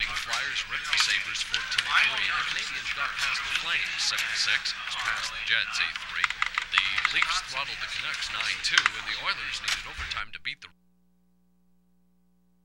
TV Futz On Sports